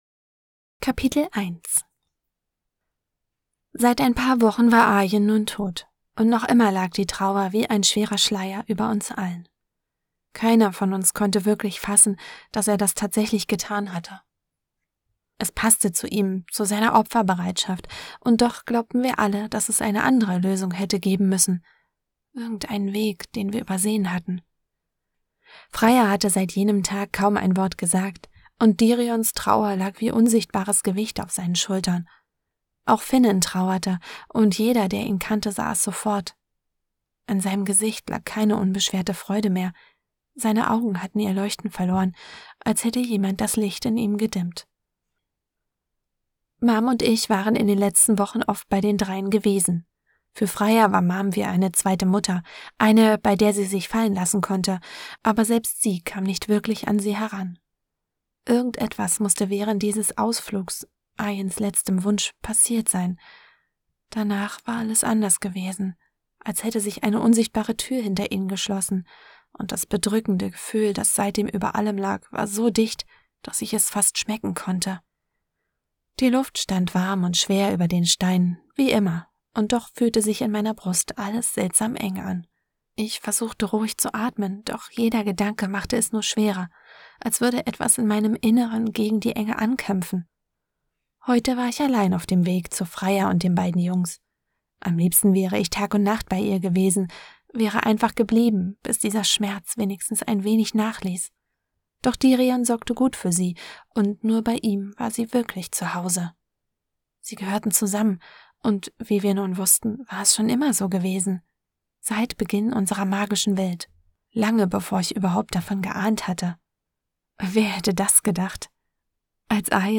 Dein-Unsterblich-Hoerprobe.mp3